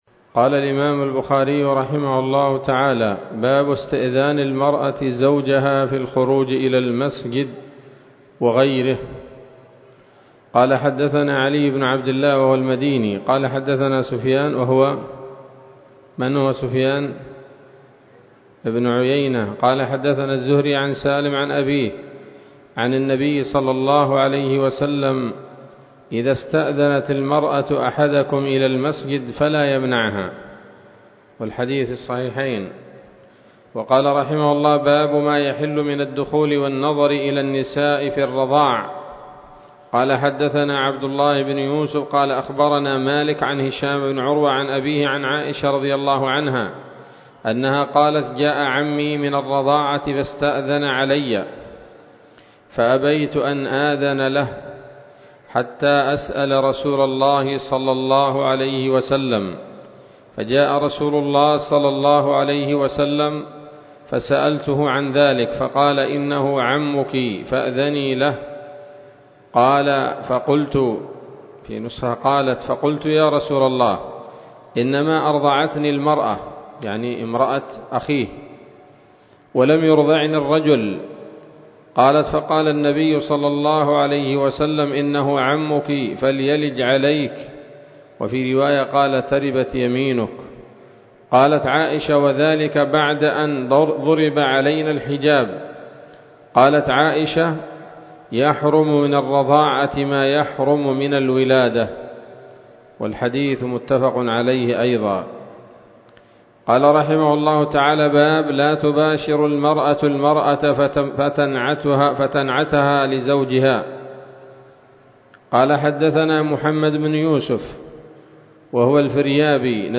الدرس الثاني والتسعون من كتاب النكاح من صحيح الإمام البخاري